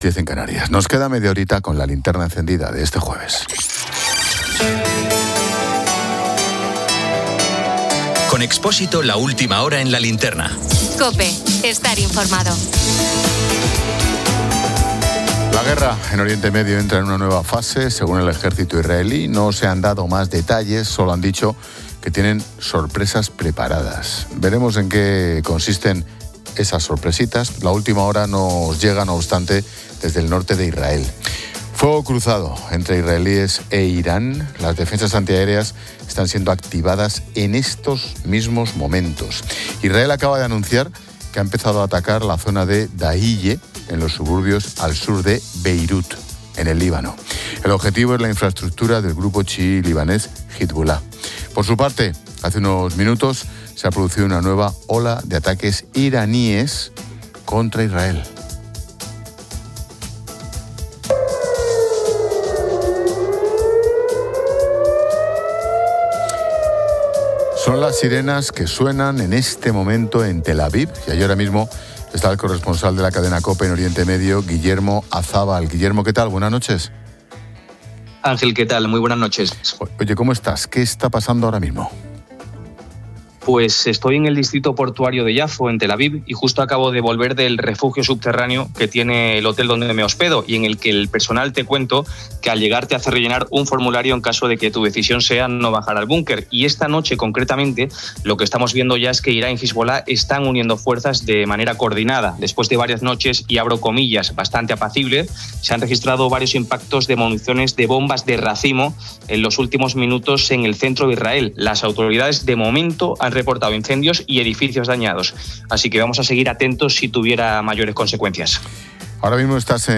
Un corresponsal informa de fuego cruzado en la frontera con Líbano, donde tanques israelíes entran en territorio libanés y un oficial israelí resulta herido. Este conflicto impulsa al alza los precios mundiales del petróleo y el gas.